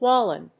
音節Wal・loon 発音記号・読み方
/wɑlúːn(米国英語), wɔlúːn(英国英語)/